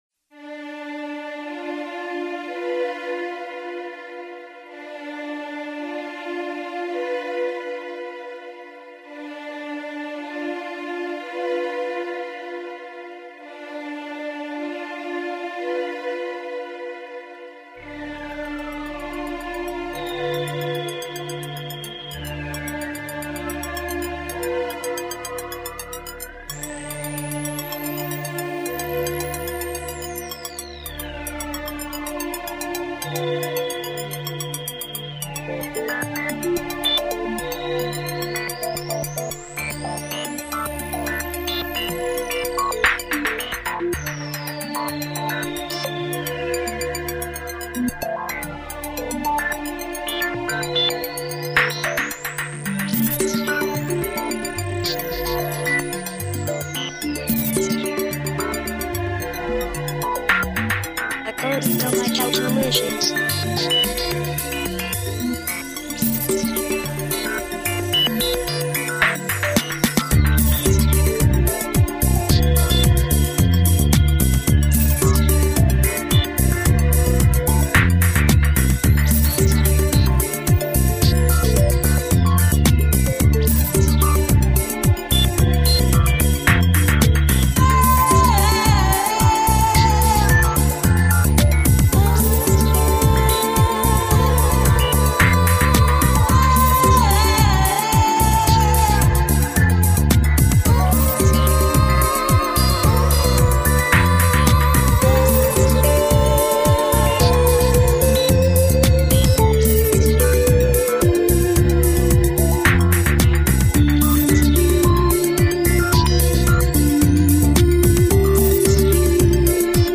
Genre: World/Trip Hop/Chant